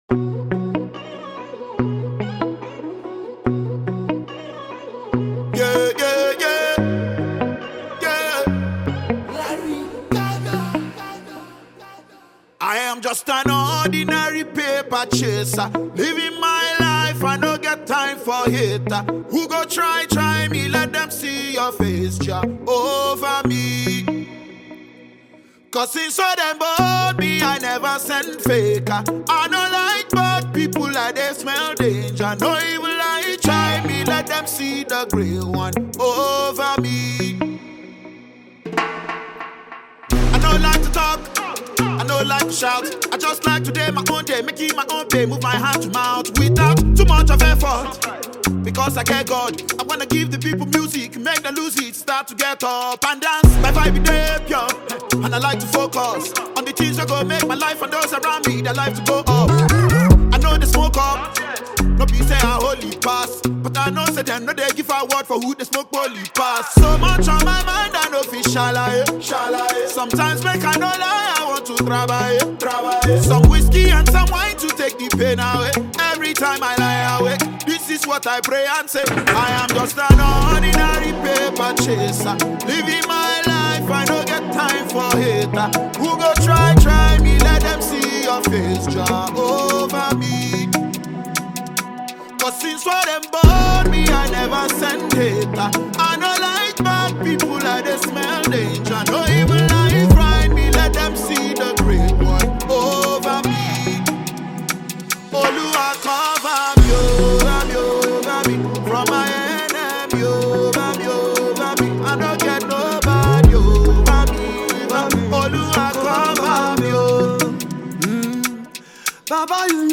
March 18, 2025 Publisher 01 Gospel 0